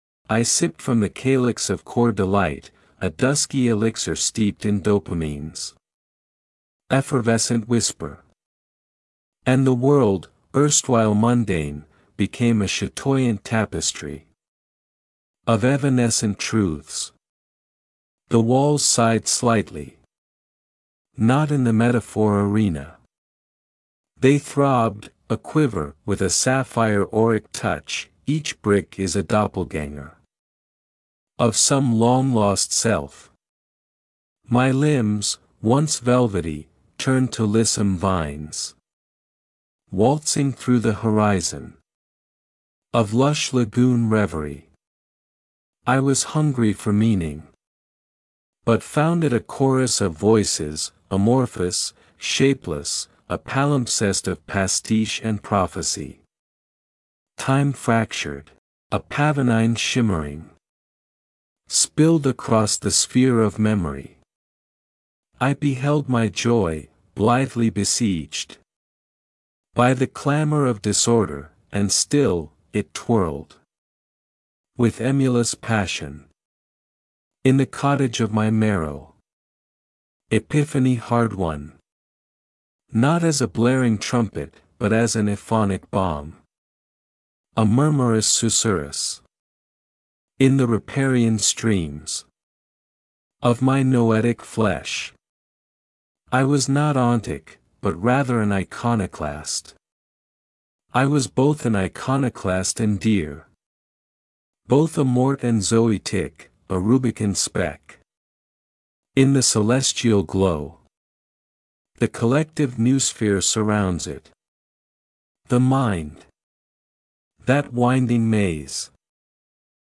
I also enjoyed this classical version of The House of the Rising Sun.